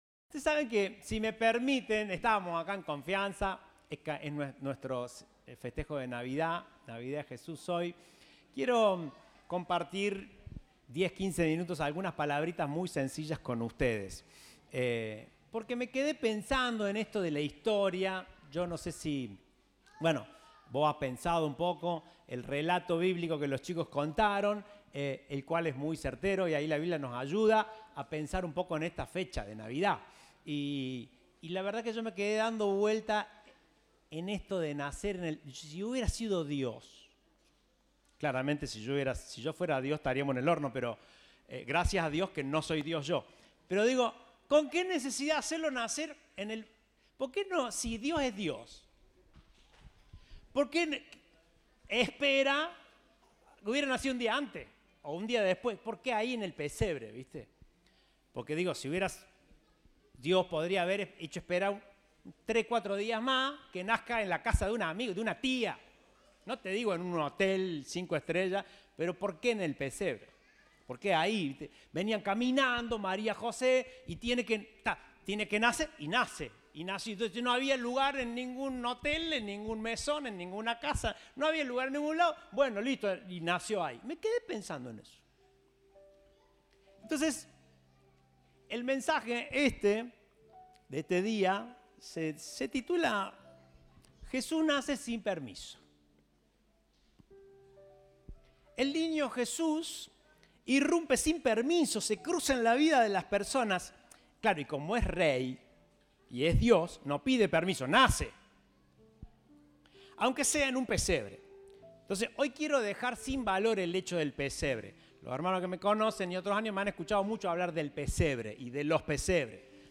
Compartimos el mensaje del Viernes 23 de Diciembre de 2022.